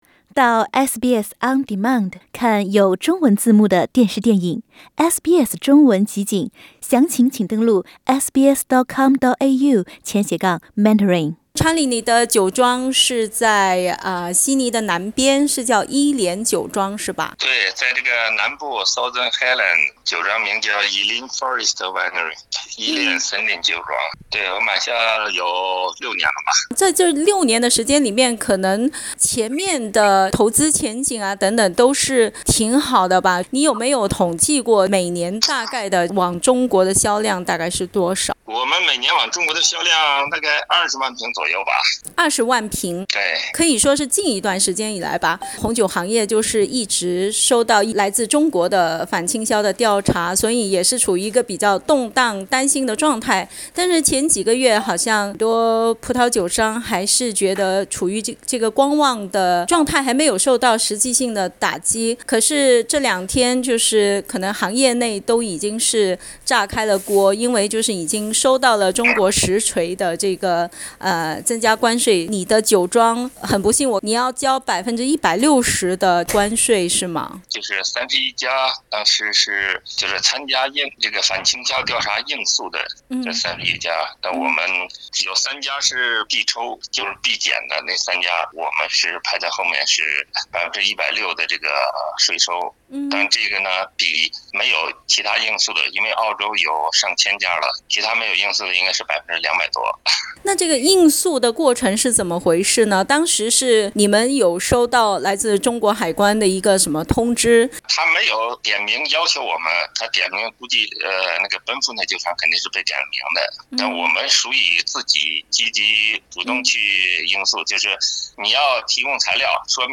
(请听采访） （本节目为嘉宾观点，不代表本台立场。）